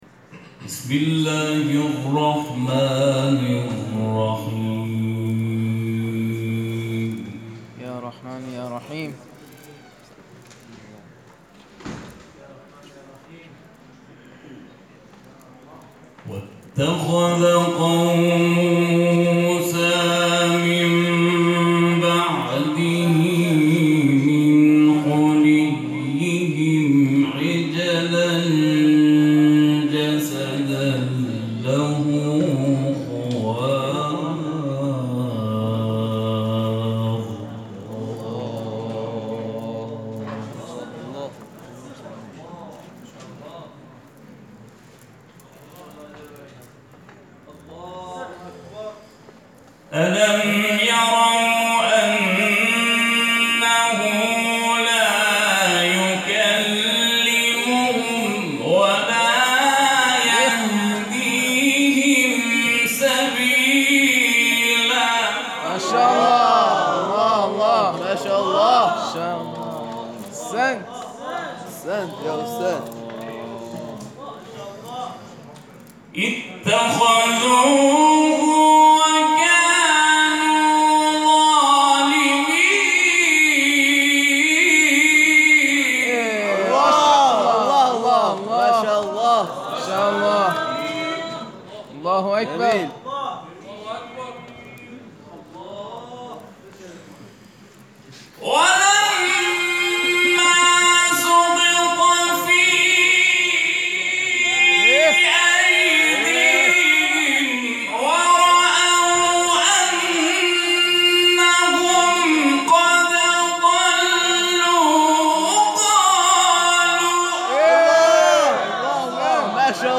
این تلاوت روز گذشته در مرحله مقدماتی مسابقات استانی اوقاف تلاوت شده است.